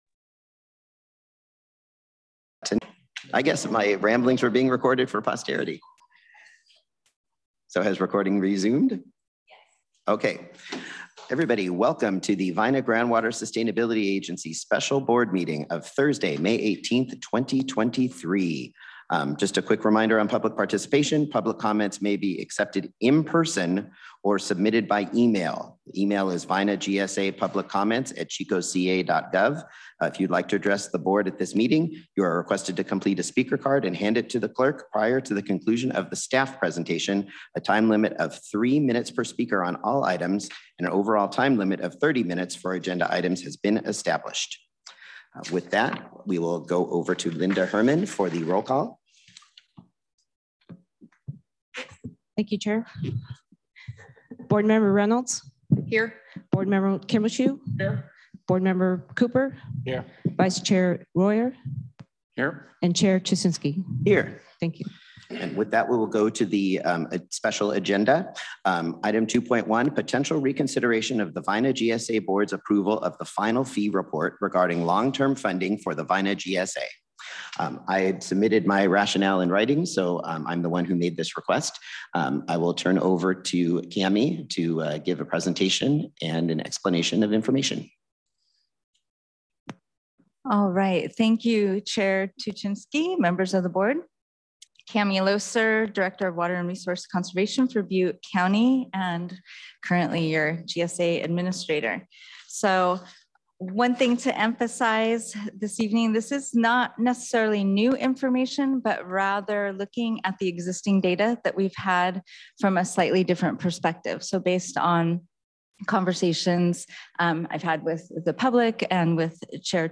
Vina GSA Board Meetings
The Vina GSA Board Meetings will are held in-person beginning at 3:30 p.m. on the second Wednesday of the month, or as otherwise scheduled by the…